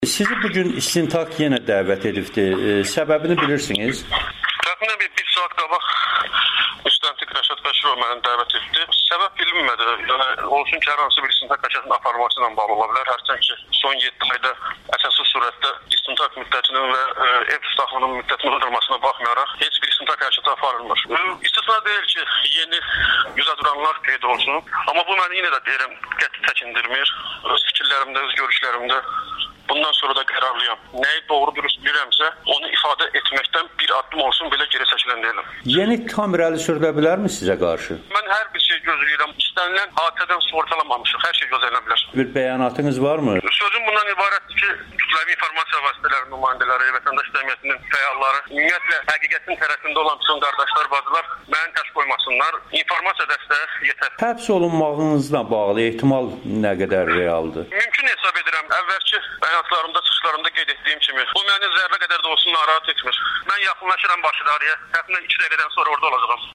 Onunla audio müsahibəni təqdim edirik.